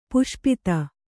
♪ puṣpita